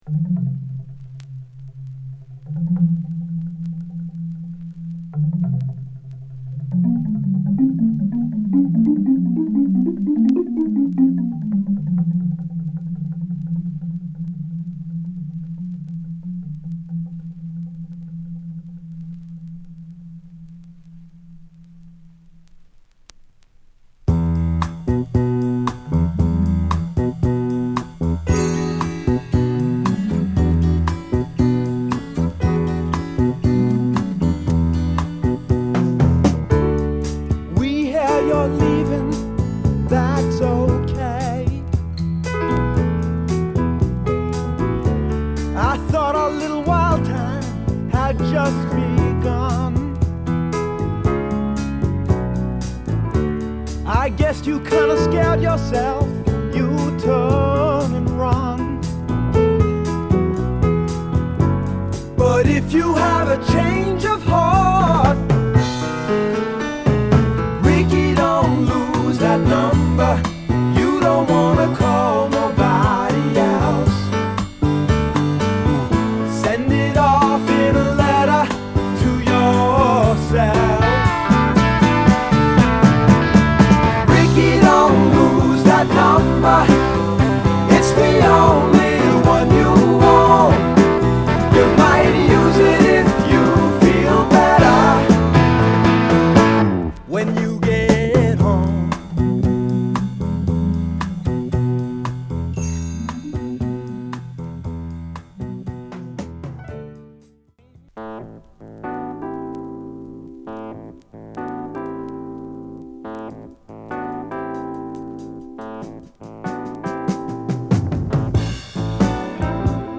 磨き上げたポップ／ロックを収録！